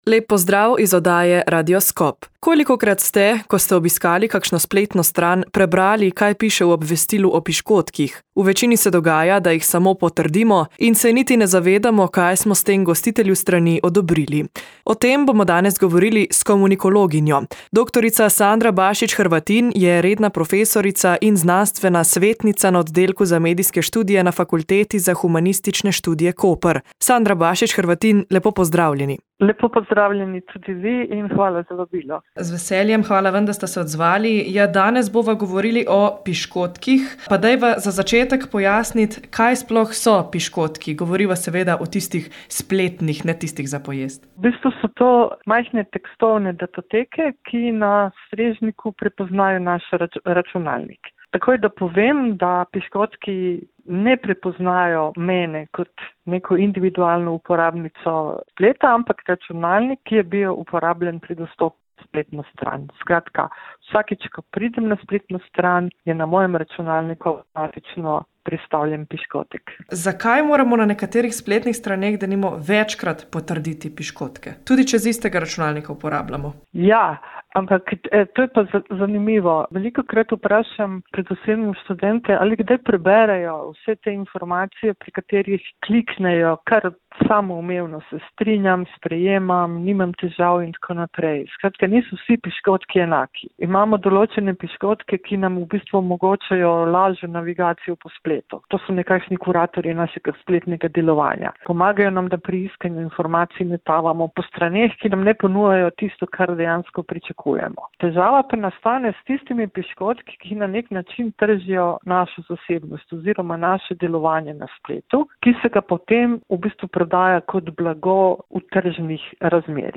V tokratni oddaji Radioskop smo se o tem pogovarjali s komunikologinjo.